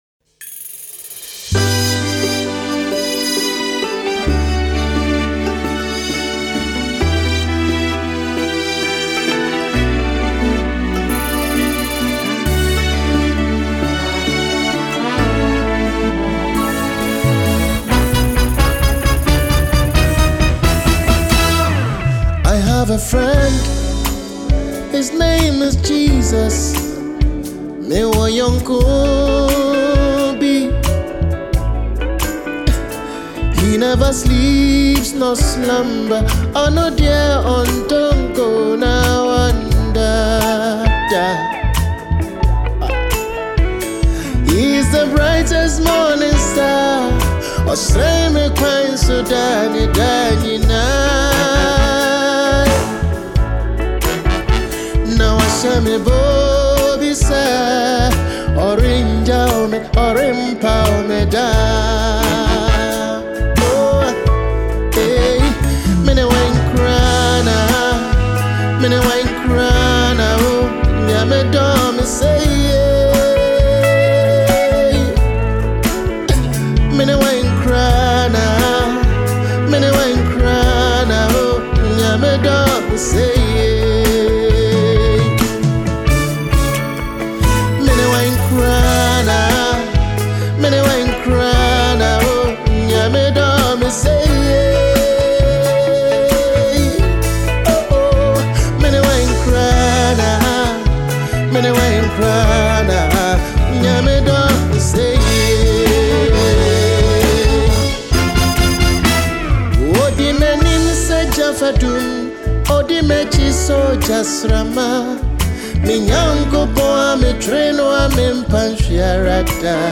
a Ghanaian highlife singer